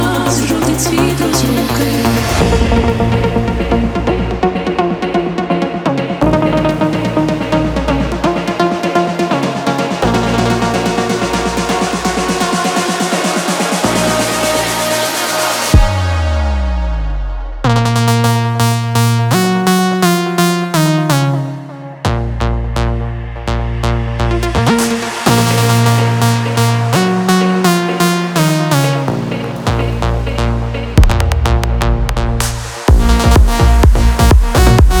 Жанр: Техно / Украинские